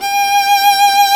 Index of /90_sSampleCDs/Roland - String Master Series/STR_Viola Solo/STR_Vla2 % marc